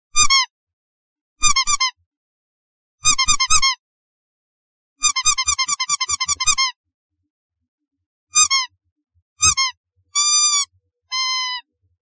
El ruido que hace un patito de goma.